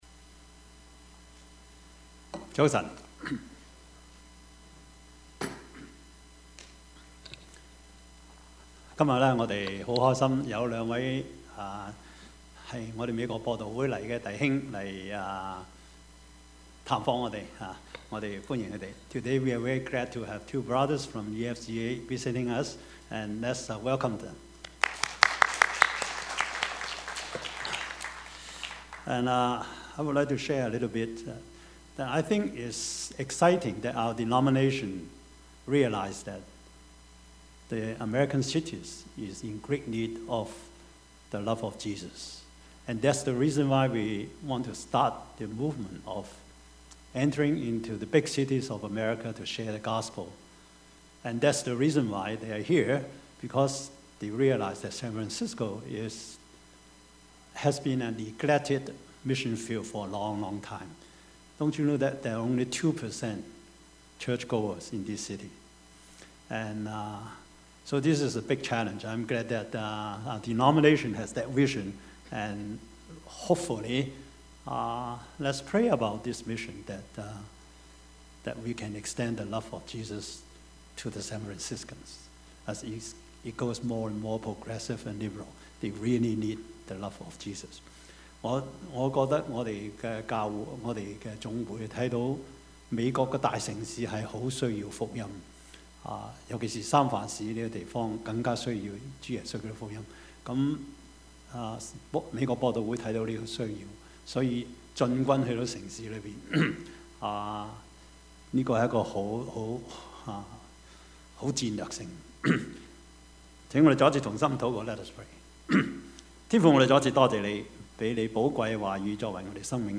Service Type: 主日崇拜
Topics: 主日證道 « 以貎取人 更重與更多 »